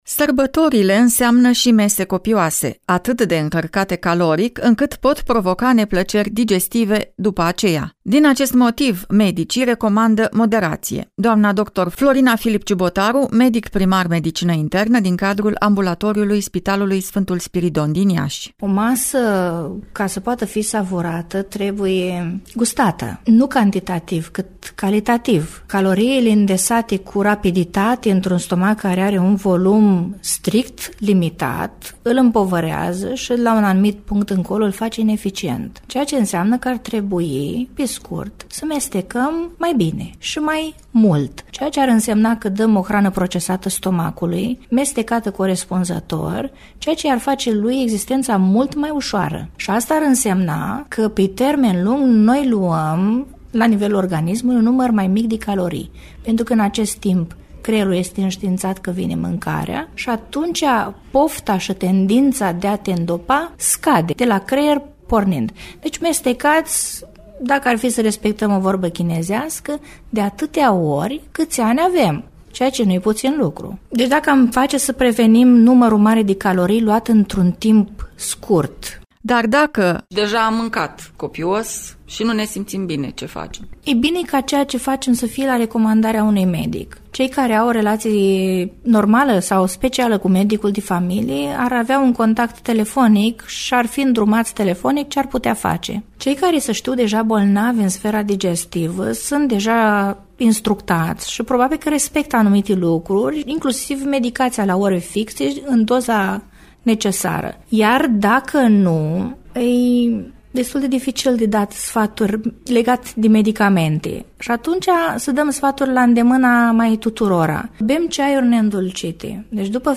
medic primar medicină internă